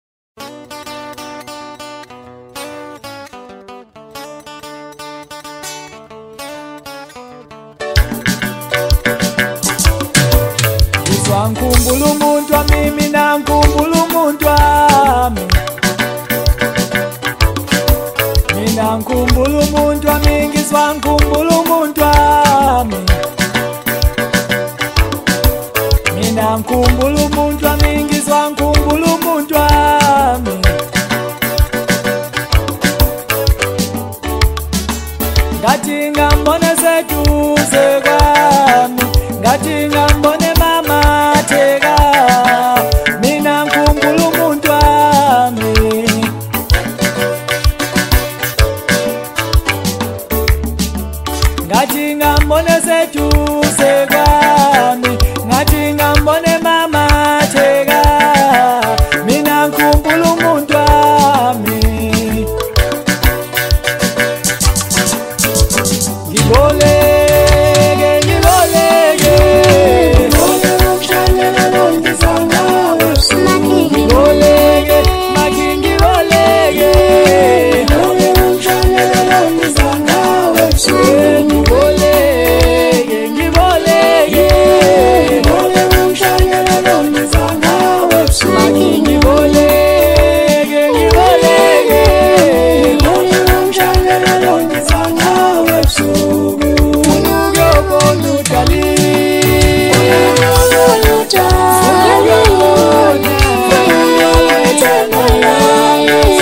Home » Hip Hop » Latest Mix » Maskandi
smooth sound, balanced mood